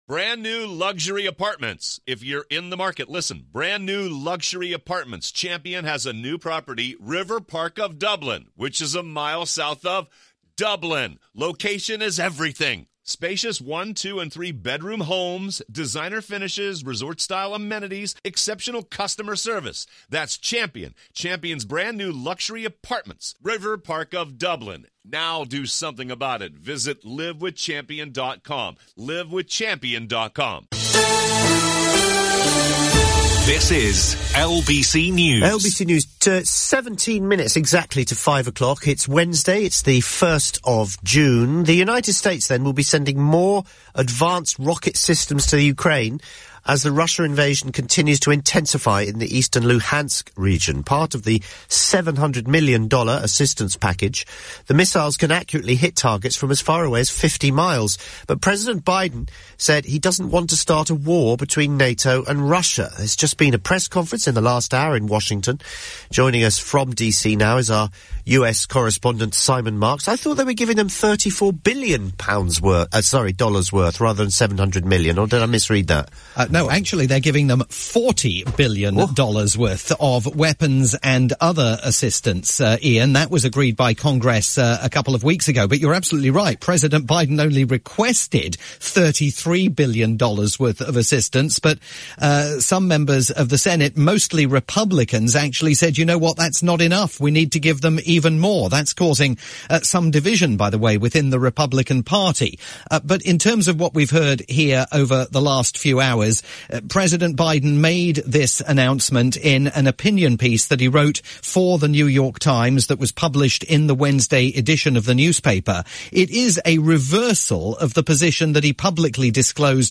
live update for the UK's rolling news station LBC News